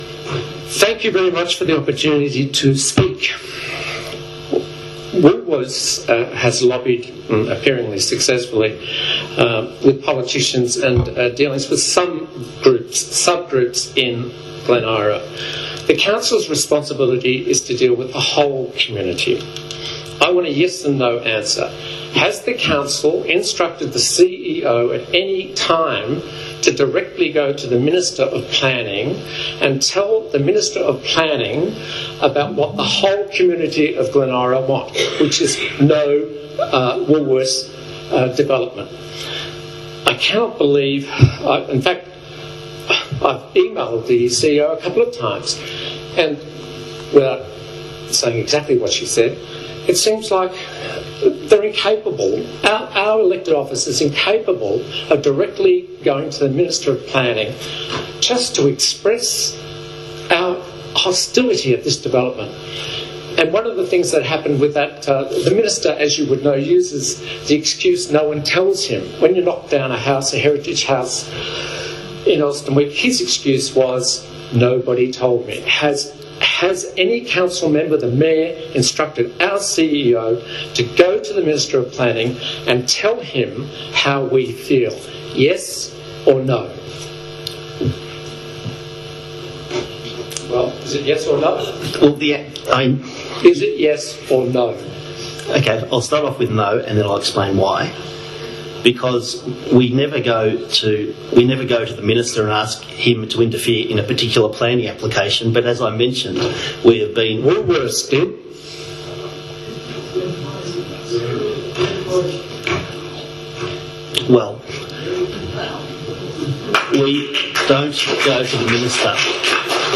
Another question from a residents at the last council meeting illustrates this perfectly. Please also note the response from our current Mayor.